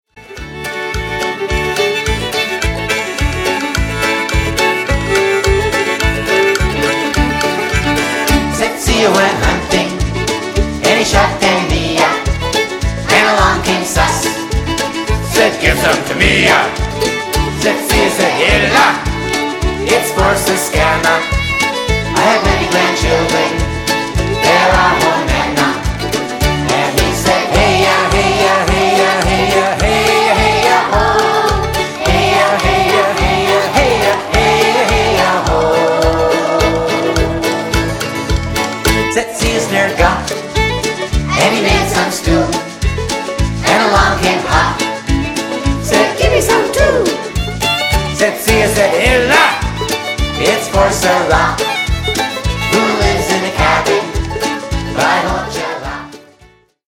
Lots of traditional Métis type fiddle music.